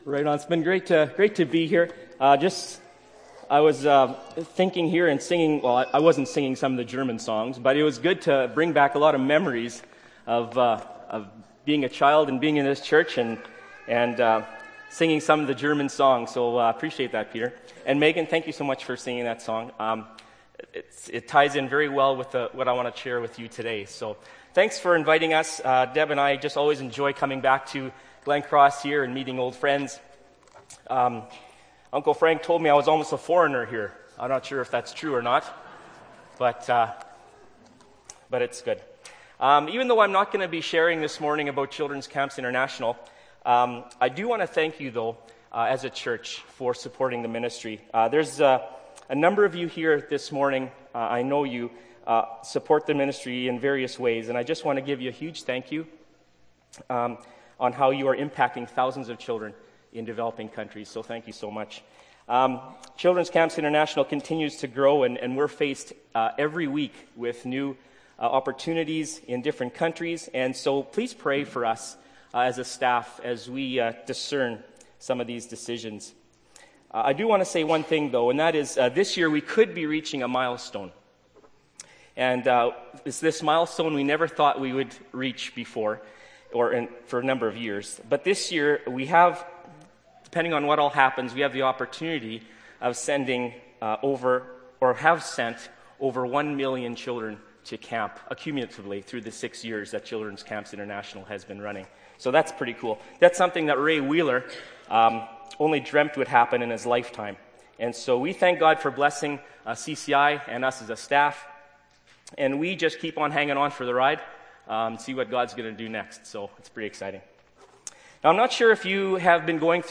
Dec. 23, 2012 – Sermon – Glencross Mennonite Church